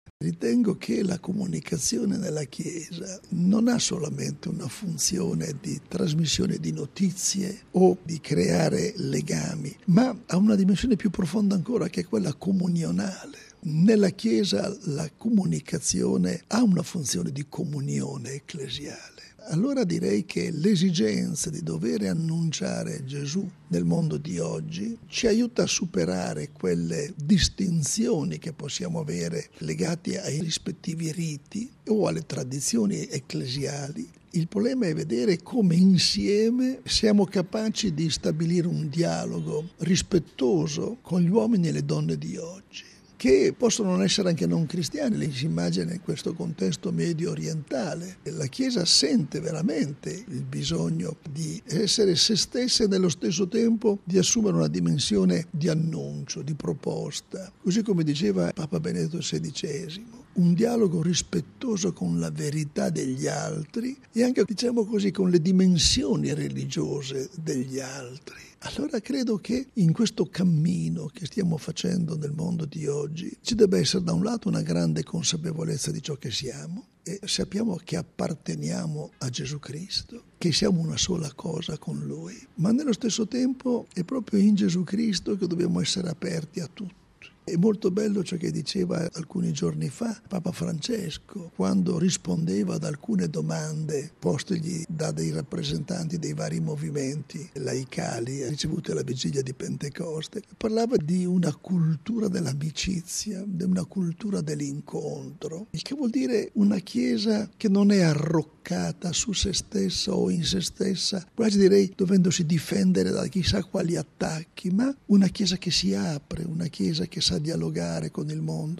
Con questa idea di fondo si apre oggi ad Amman, in Giordania, un Seminario per vescovi, sacerdoti e laici sul tema “I media arabi cristiani al servizio della giustizia, della pace e dei diritti umani”, che vedrà la partecipazioni di esperti delle varie Chiese locali. Tra le personalità di spicco, il patriarca latino di Gerusalemme, mons. Fouad Twal, e l’arcivescovo Claudio Maria Celli, presidente del Pontificio Consiglio delle Comunicazioni Sociali.